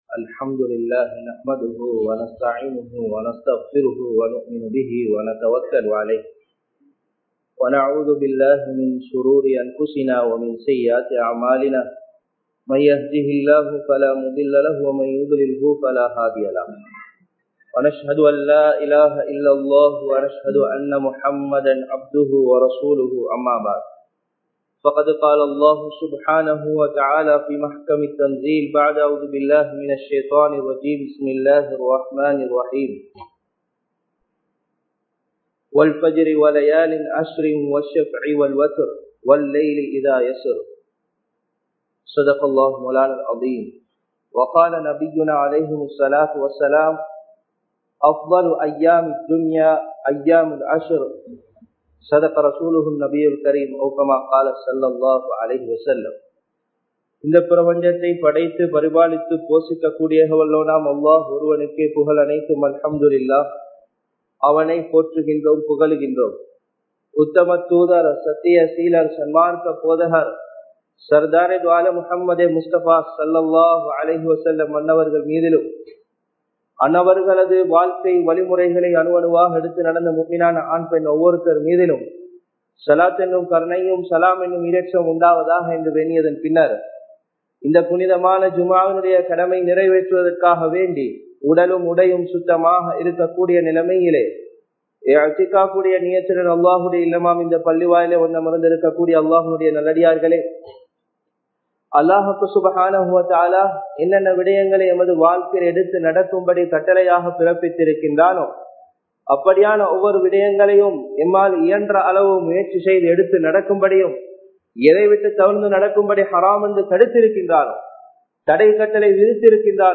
துல்ஹிஜ்ஜாவின் முதல் 10 நாட்கள் (First 10 Holy Days of Dhul Hijjah) | Audio Bayans | All Ceylon Muslim Youth Community | Addalaichenai